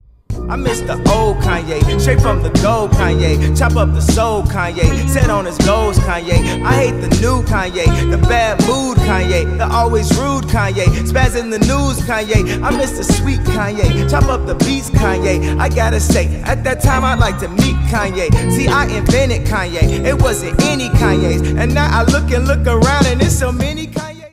ремиксы , рэп